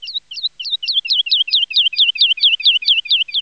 birdsin3.wav